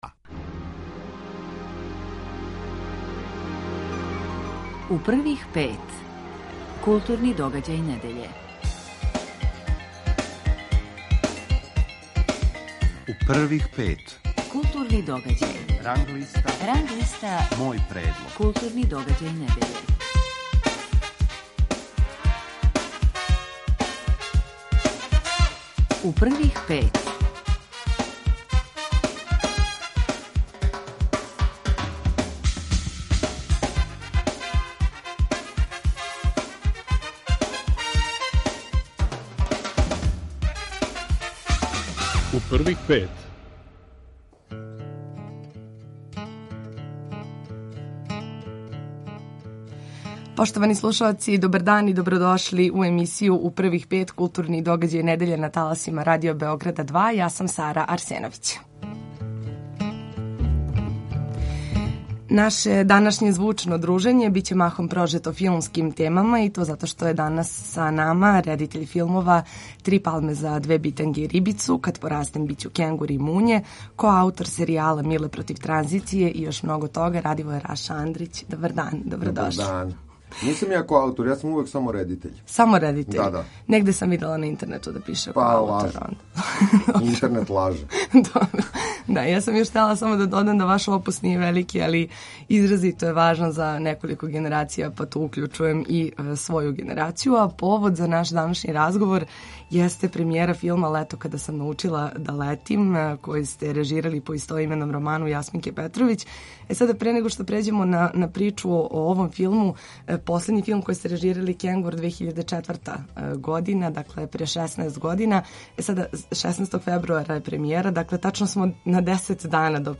Гост емисије је Радивоје Раша Андрић.